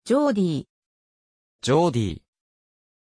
Pronunciación de Geordie
pronunciation-geordie-ja.mp3